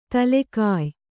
♪ tale kāy